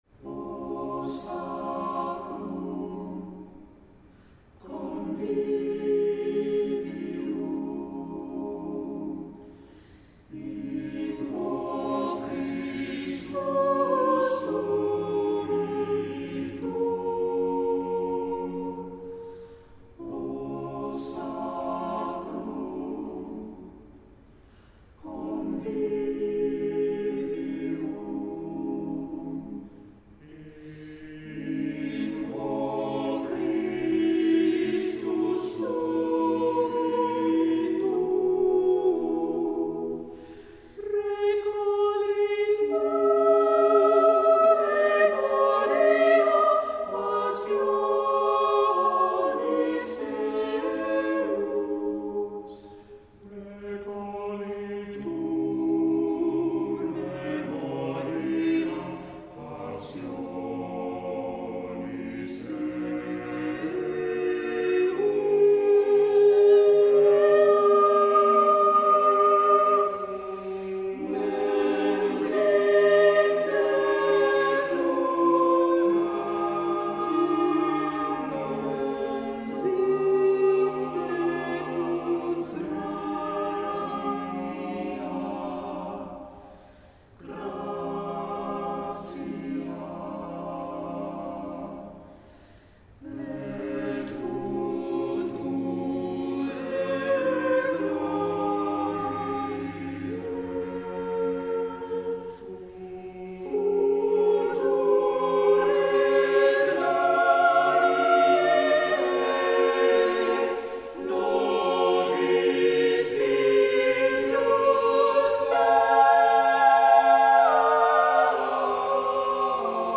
Voicing: "SATB"